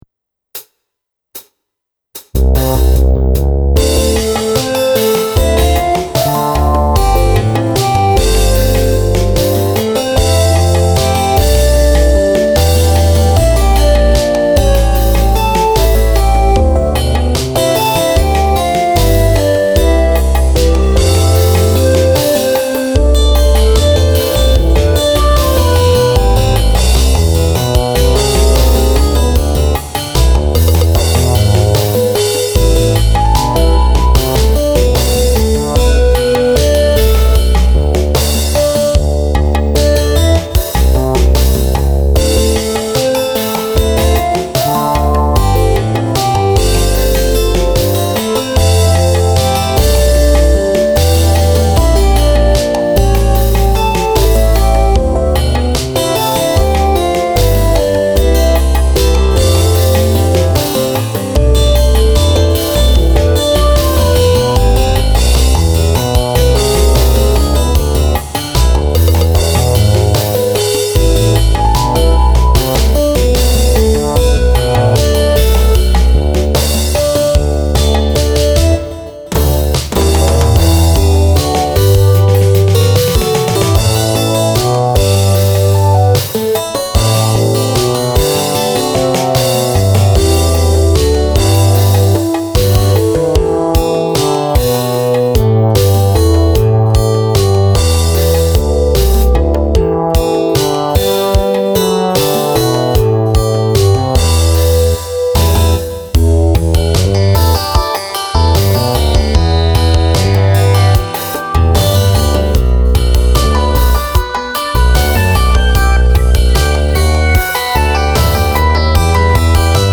テーマのメロディーが繰り返すことなく先へ先へと展開していく。